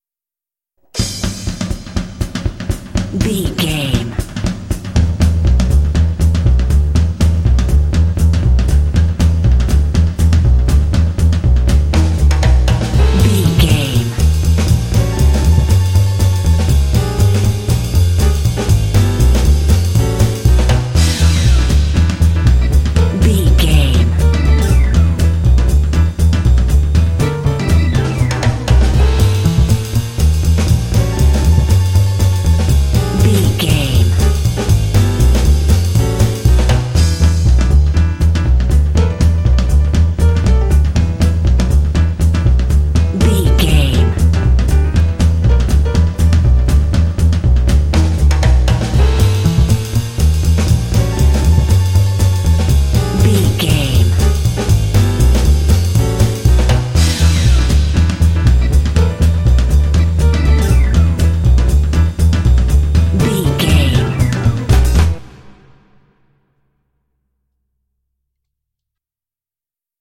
Uplifting
Aeolian/Minor
Fast
energetic
lively
cheerful/happy
double bass
piano
electric organ
big band
jazz